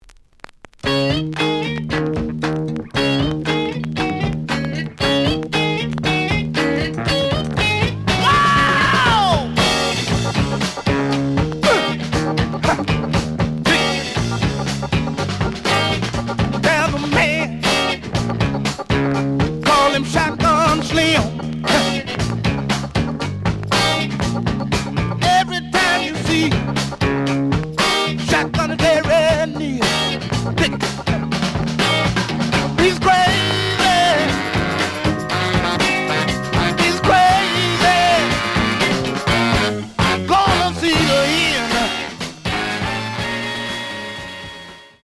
The audio sample is recorded from the actual item.
●Genre: Funk, 60's Funk
Some click noise on both sides due to scratches.)